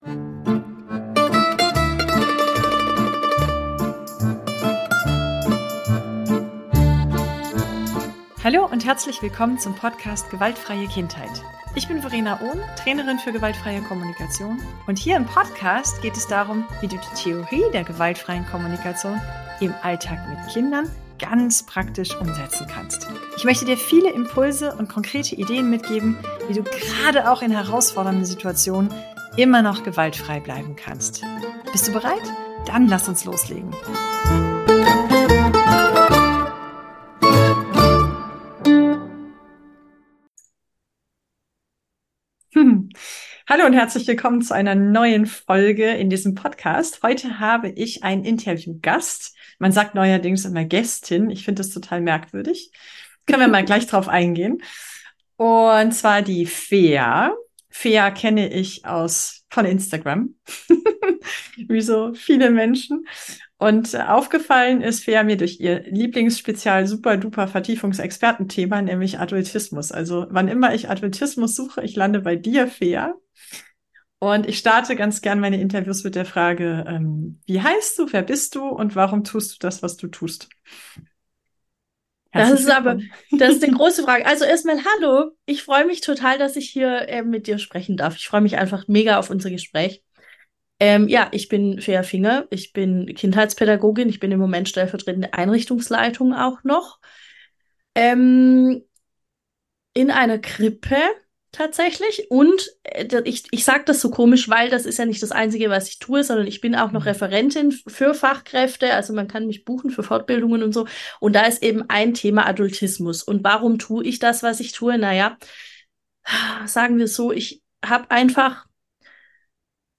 021 Adultismus im Alltag - Interview